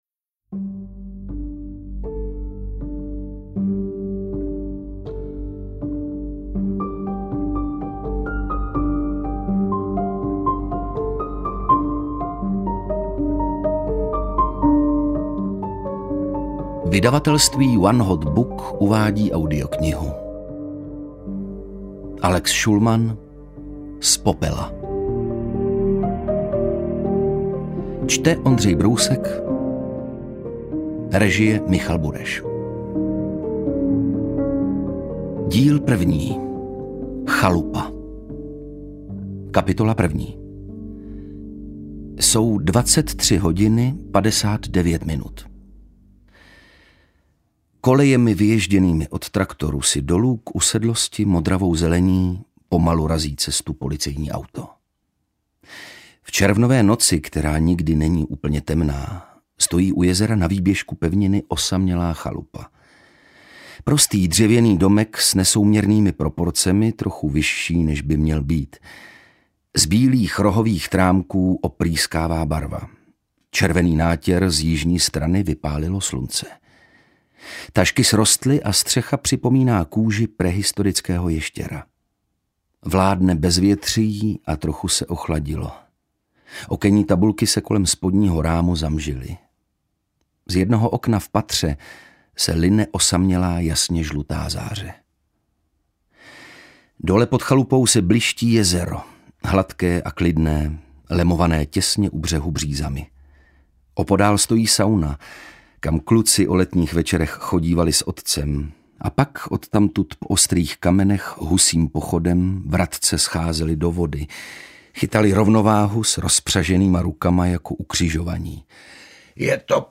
Interpret:  Ondřej Brousek
AudioKniha ke stažení, 27 x mp3, délka 6 hod. 49 min., velikost 363,0 MB, česky